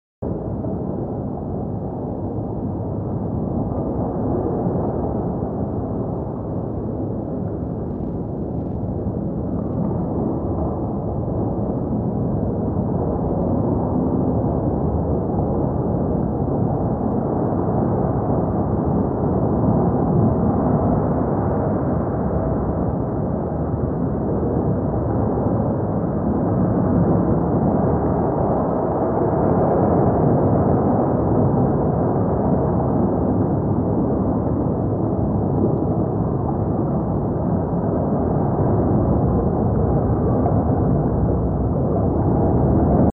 Synth Submarine Interior; Reverberant, Very Low Frequency Rumble.